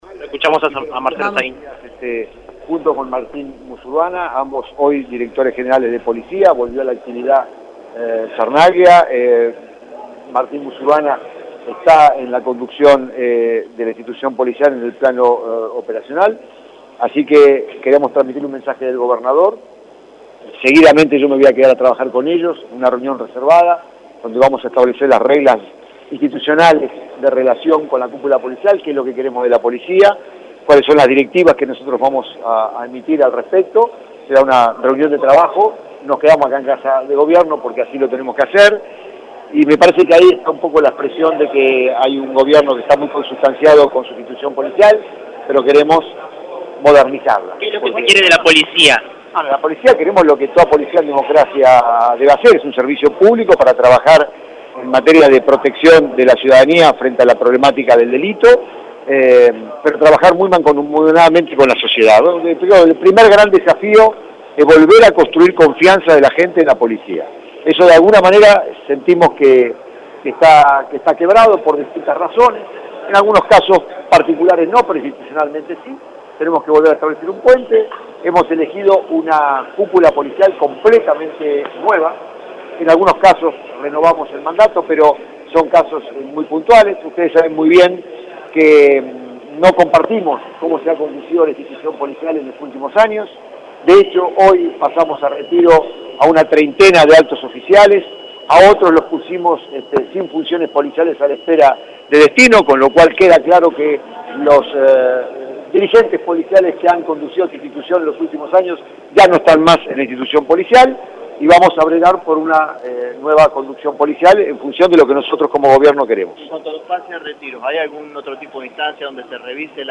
Sucedió en un acto en la Casa Gris, donde citaron a los distintos jefes policiales y los obligaron a depositar su celular en un sobre antes de ingresar al salón.
Si bien se había dicho que Saín no iba a tomar contacto con la prensa, el ministro dialogó brevemente y lanzó potentes dardos contra los funcionarios actuales de la fuerza.